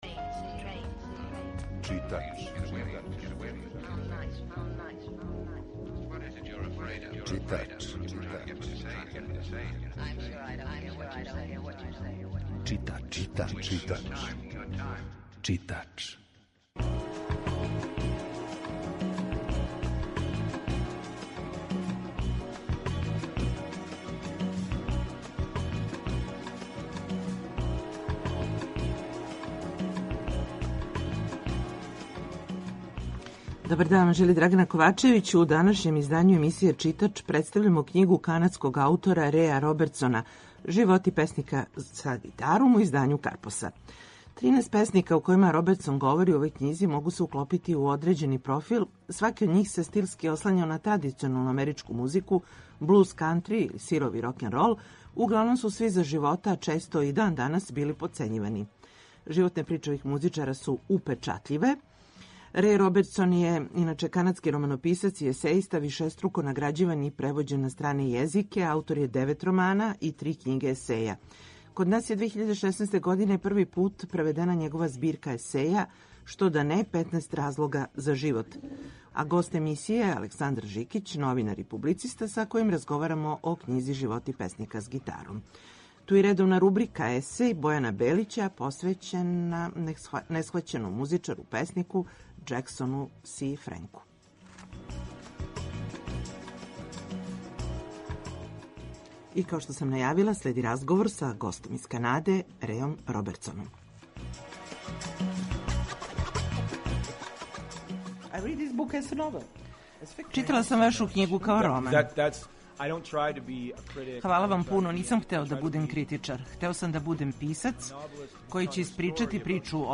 У емисији Читач чућете разговор са Реј Робертсоном који је прошле године боравио у Београду.